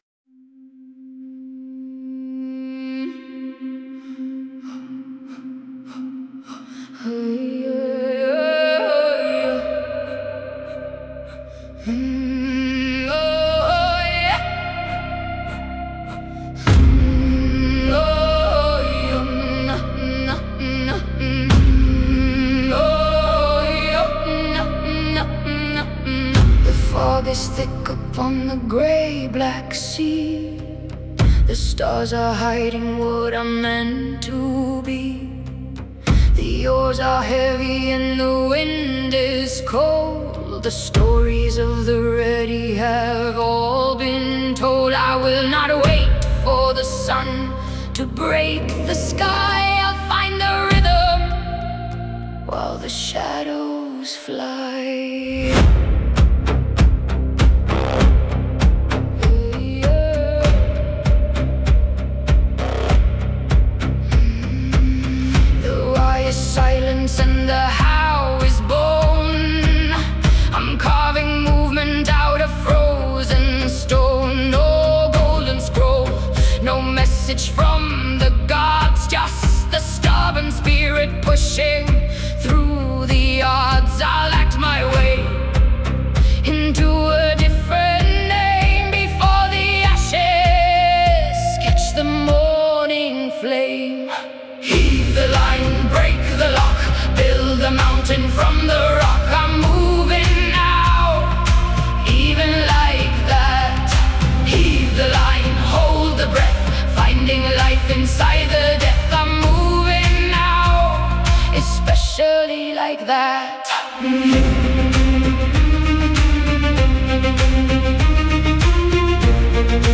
Every chapter has a song.